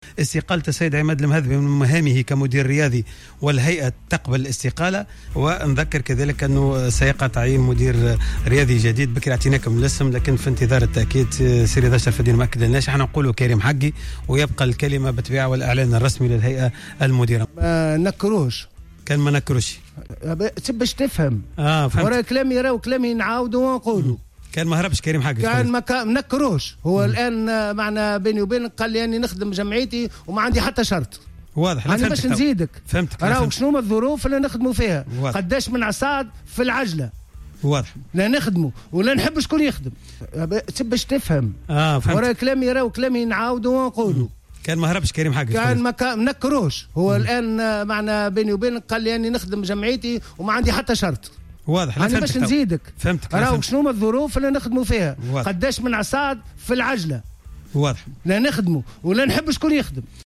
صرح الدكتور رضا شرف الدين لدى حضوره في حصة خاصة على الجوهرة أف أم أنه قد اتصل باللاعب السابق للفريق كريم حقي للإضطلاع بخطة مدير رياضي صلب الفريق بعد إستقالة عماد المهذبي.